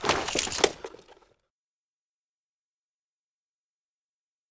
pkm_bullet.mp3